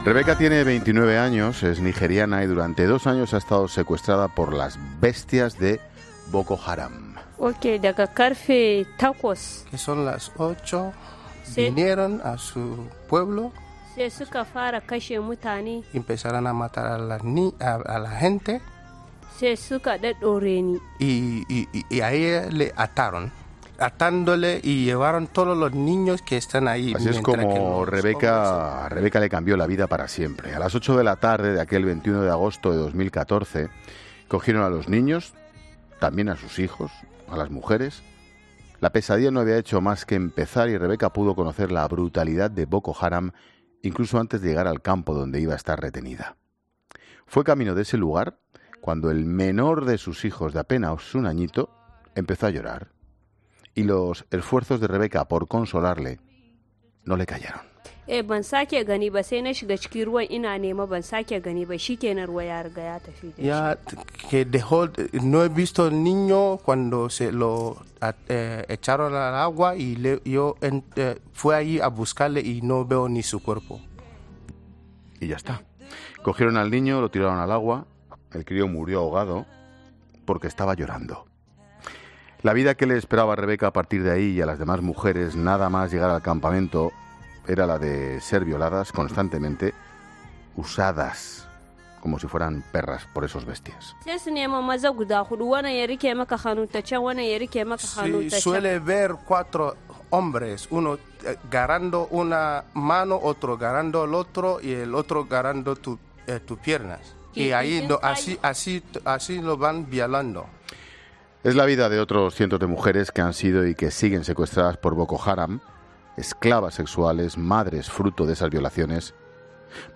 periodista experto en el terrorismo de Boko Haram, en 'La Tarde'